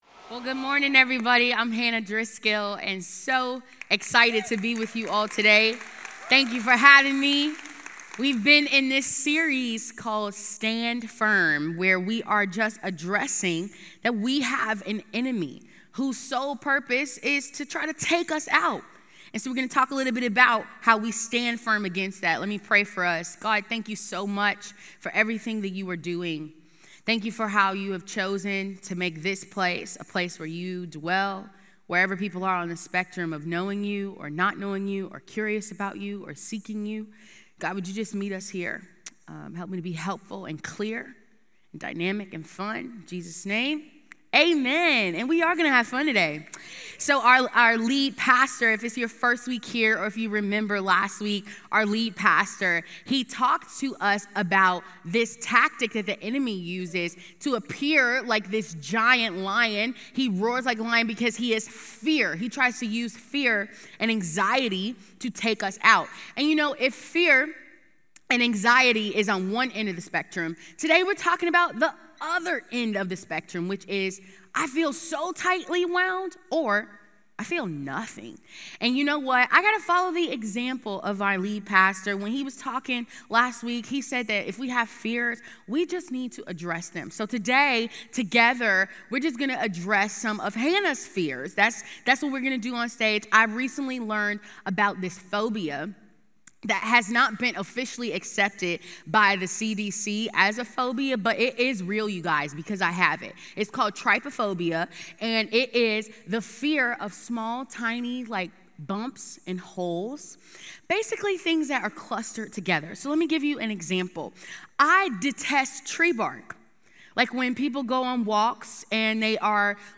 Recorded live at Crossroads Church in Cincinnati, Ohio.